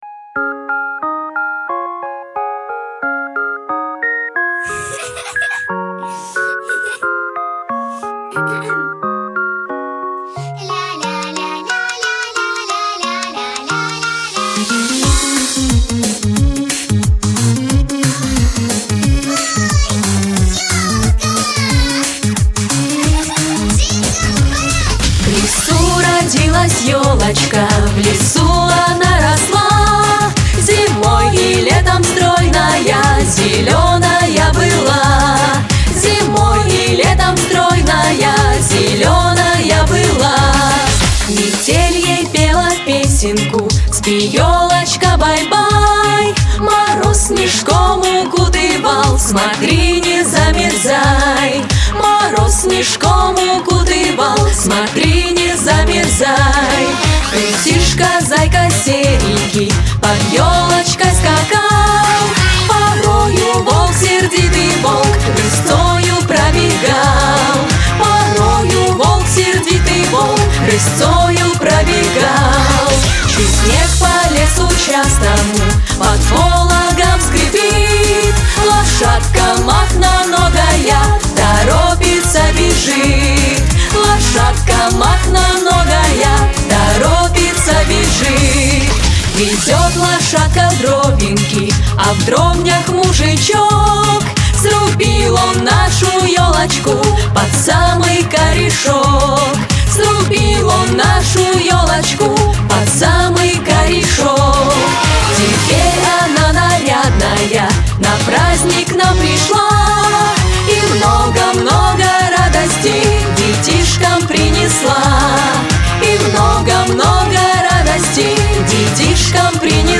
Современная_обработка_-_В_лесу_родилась_елочка